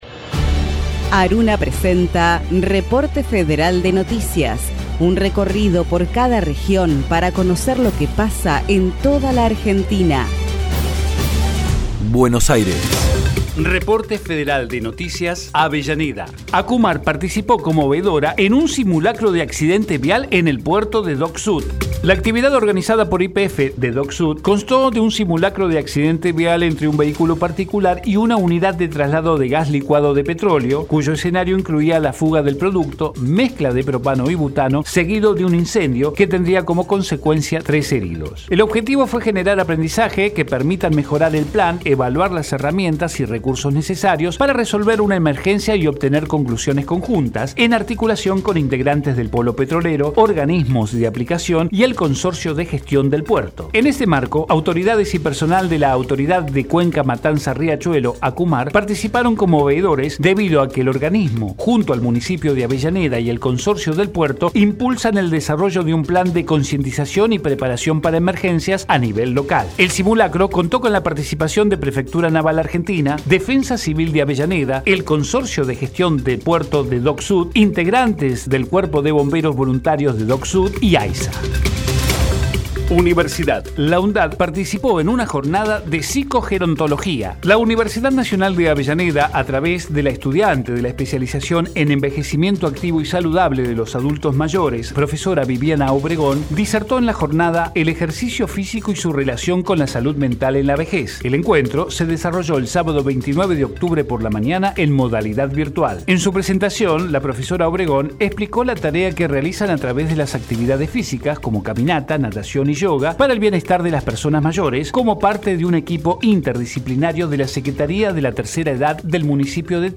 Reporte Federal Texto de la nota: Radio UNDAV - Reporte Federal de noticias Producción colaborativa de ARUNA Las emisoras de universidades nacionales que integran la Asociación de Radios Universitarias Nacionales Argentinas (ARUNA) emiten un informe diario destinado a brindar información federal con la agenda periodística más destacada e importante del día. Un programa que contiene dos noticias por cada radio participante, una noticia institucional de las universidades nacionales y otra local o provincial de interés social, con testimonios de las y los protagonistas locales.